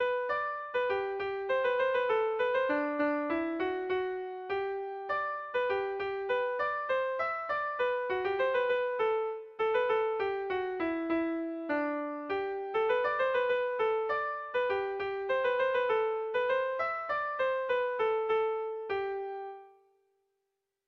Dantzakoa
Zortziko txikia (hg) / Lau puntuko txikia (ip)
ABDE